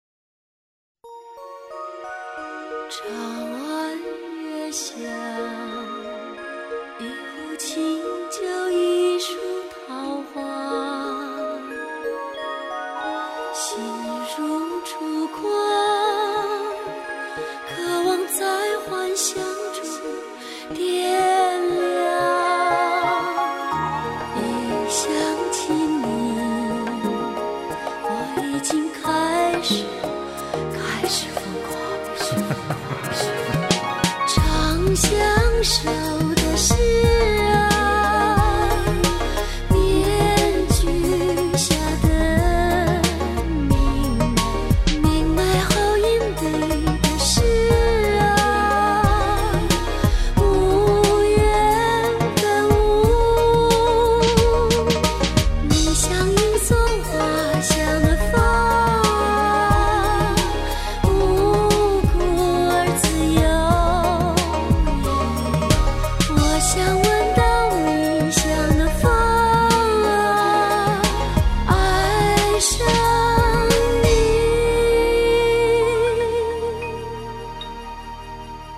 专辑类别：soundtrack